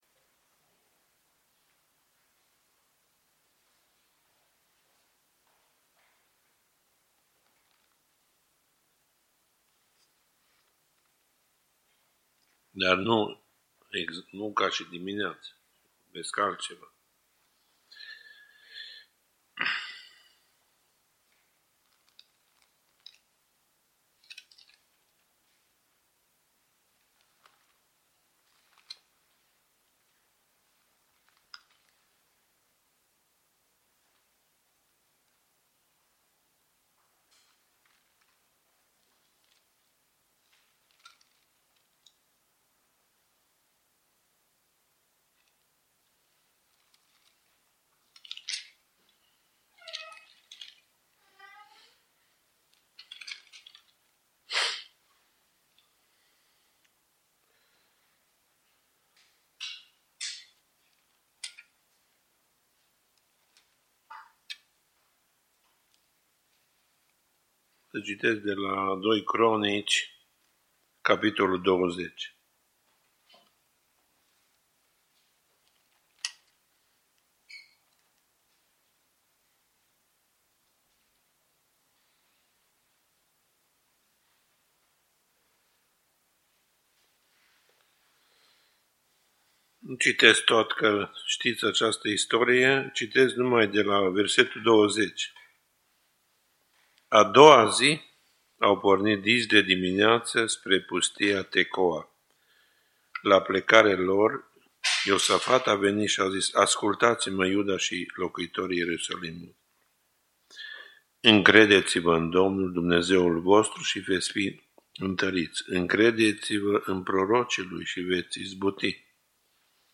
Elhangzott Nagyváradon, 20 20 . július 5-é n. PDF: Hadizsákmány mint építőanyag https